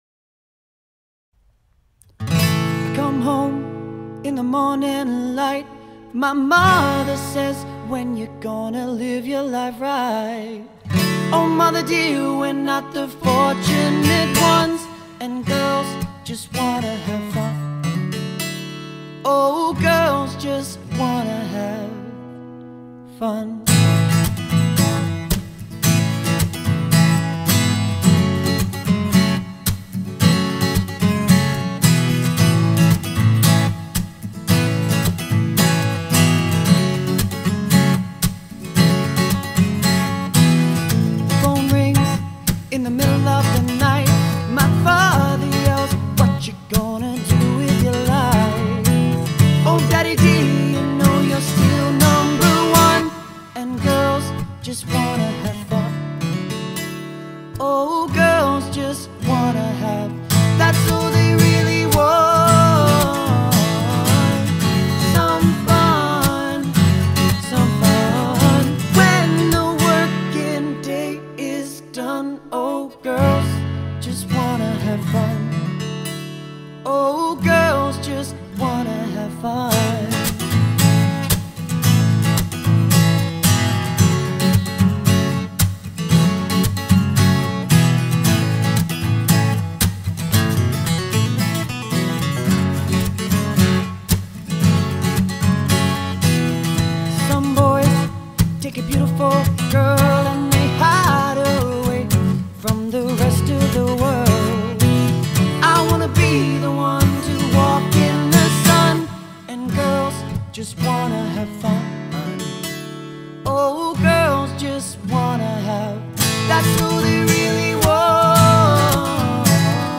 Pop/Rock and Modern Country sound